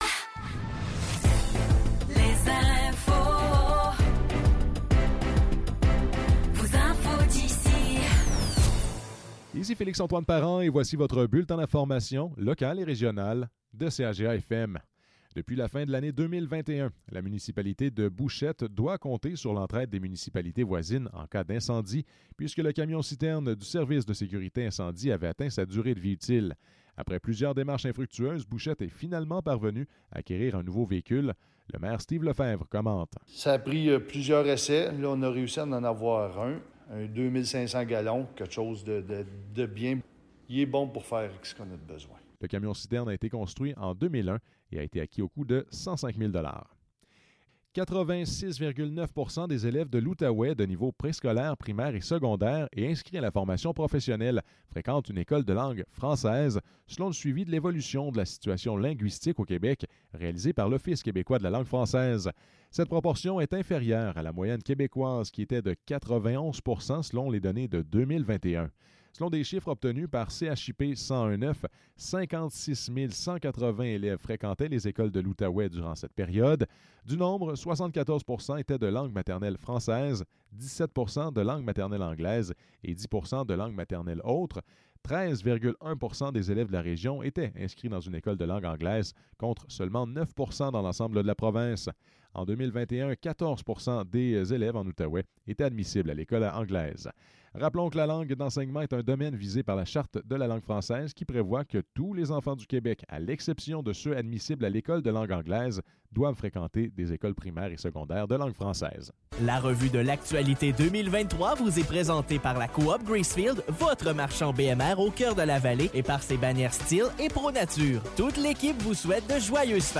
Nouvelles locales - 3 janvier 2024 - 16 h